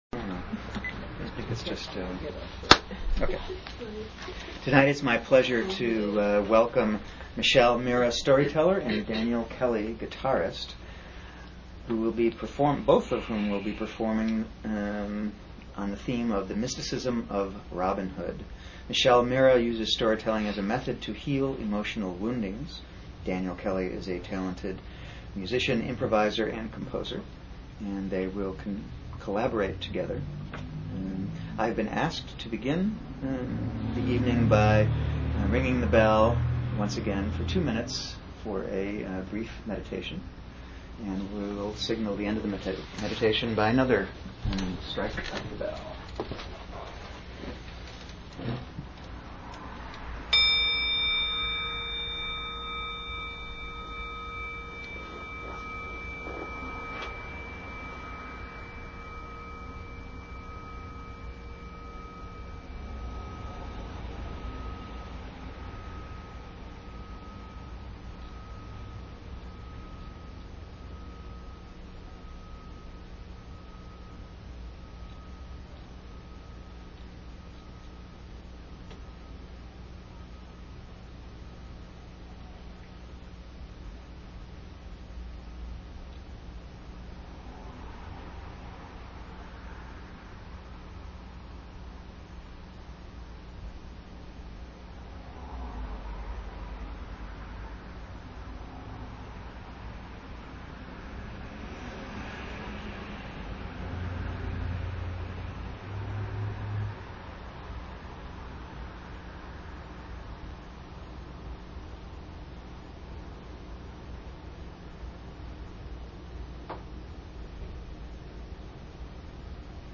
Archive of an event at Sonoma County's largest spiritual bookstore and premium loose leaf tea shop.
acoustic guitar. We will look at some of the spiritual insights offered, the creation of a leader, and what it shows us about presenting yourself to the world in a fearless and vulnerable way.